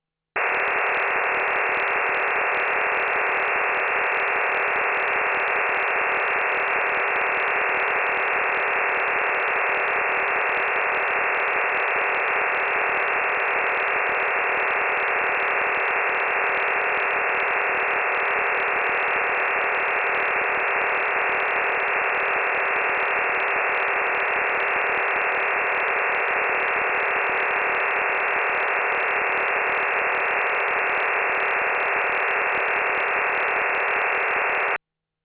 WinDRM 51-TONE OFDM MODEM (48-DATA CARRIERS + 3 REF. TONES - AMATEUR RADIO MODE) AUDIO SAMPLE WinDRM Voice Transmission using LPC10 Vocoder running 64QAM+ short interleaving back to voice encryption, vocoder & voice compressor page